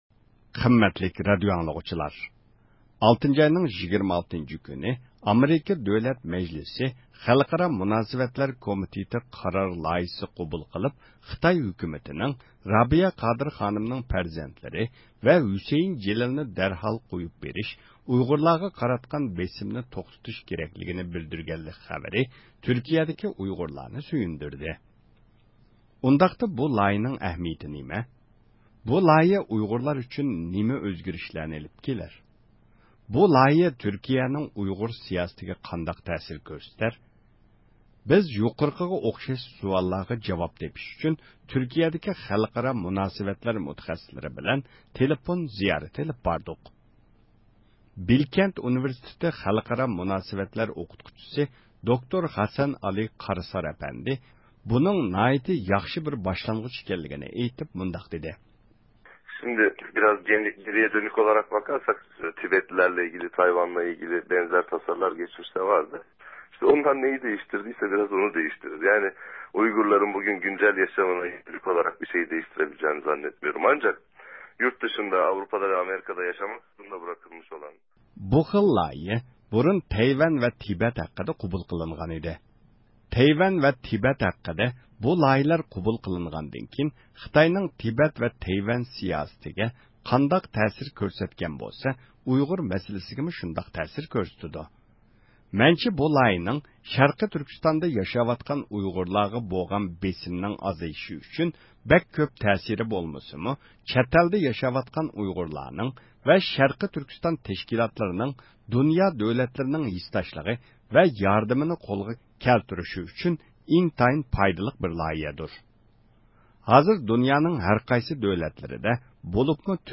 بىز يۇقىرىقىغا ئوخشاش سوئاللارغا جاۋاب تېپىش ئۈچۈن تۈركىيىدىكى خەلقئارا مۇناسىۋەتلەر مۇتەخەسسىسلىرى بىلەن تېلېفون زىيارىتى ئېلىپ باردۇق.